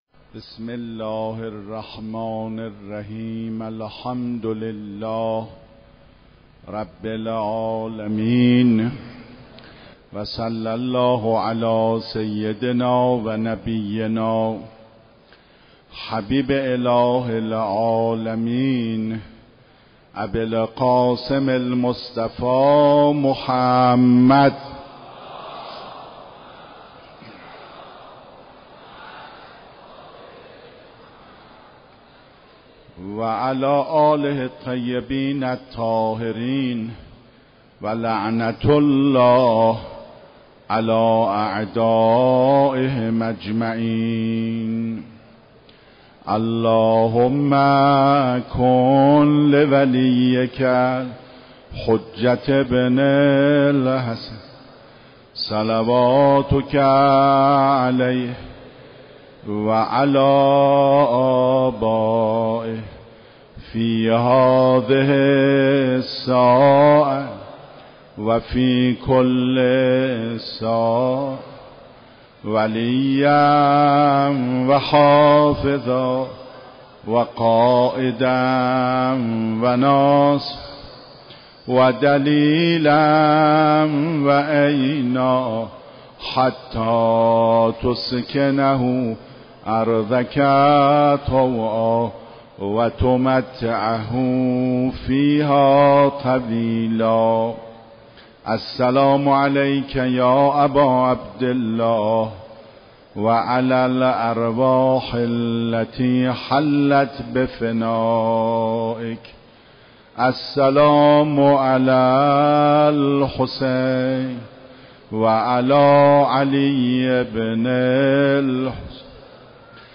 شب اول محرم 95_سخنراني